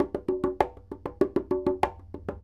44 Bongo 20.wav